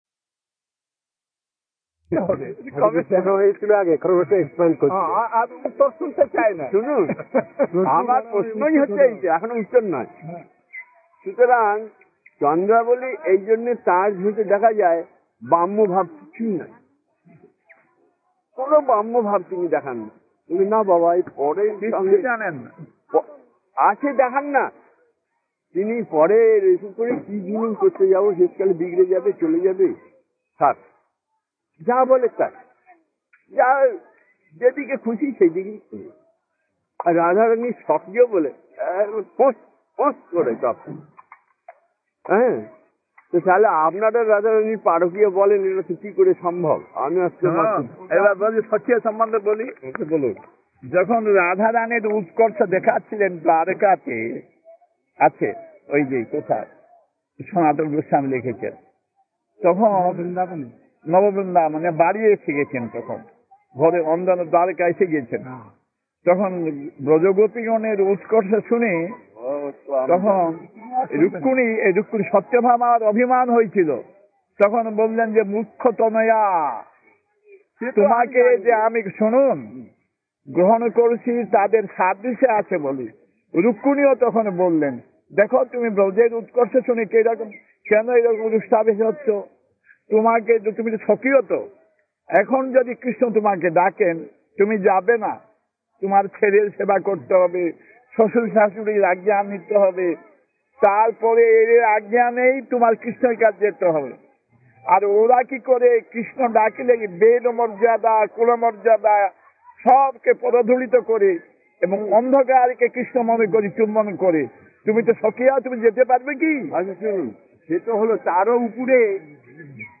Conversation About Svakiya-Parakiya